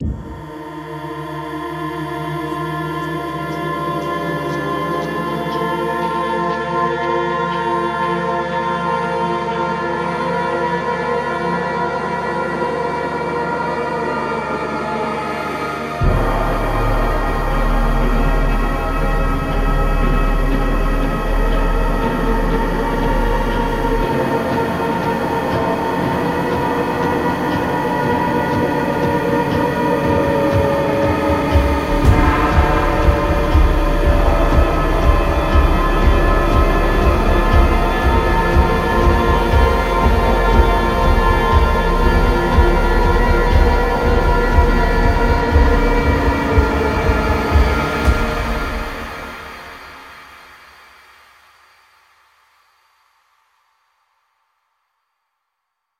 Cinematic 2